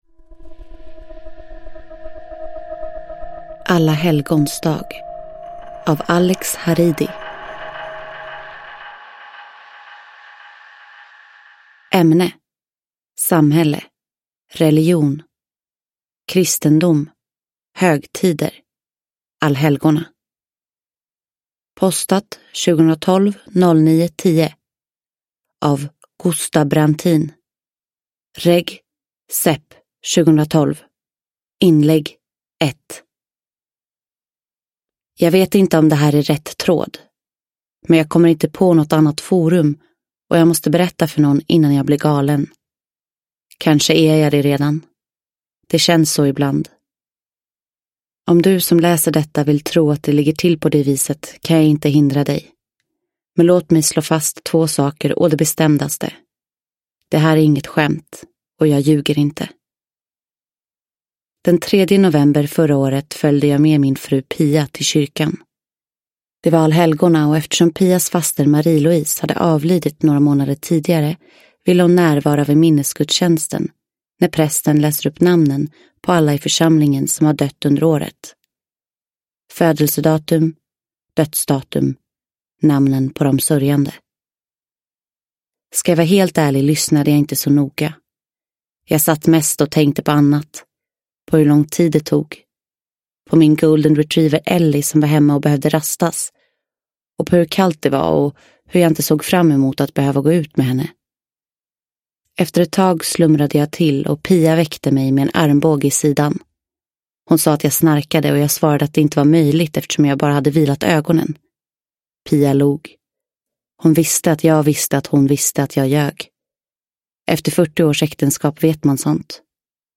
Alla helgons dag – Ljudbok – Laddas ner